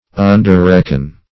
underreckon - definition of underreckon - synonyms, pronunciation, spelling from Free Dictionary
Search Result for " underreckon" : The Collaborative International Dictionary of English v.0.48: Underreckon \Un`der*reck"on\, v. t. To reckon below what is right or proper; to underrate.